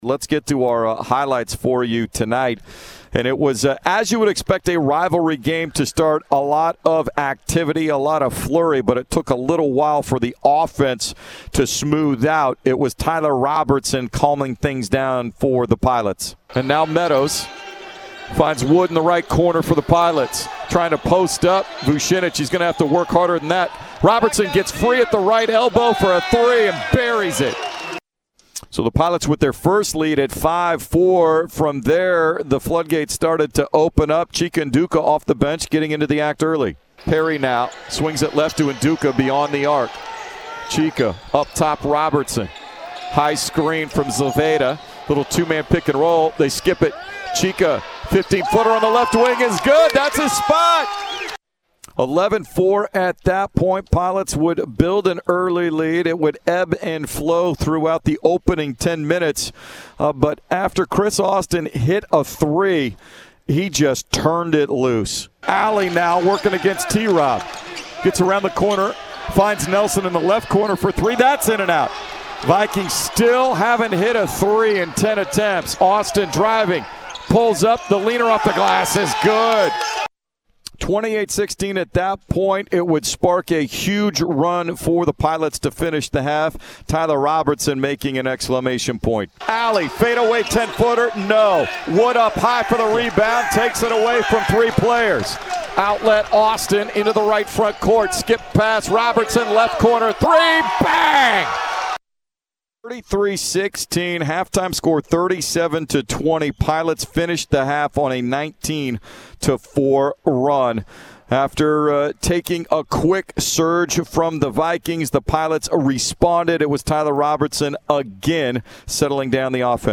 Men's Basketball Radio Highlights vs. Portland State
November 23, 2021 Radio highlights from Portland's 69-54 win at crosstown rival Portland State.